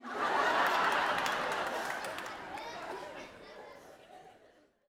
Audience Laughing-09.wav